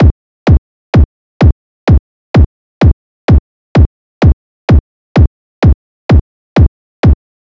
Lis Bd Loop.wav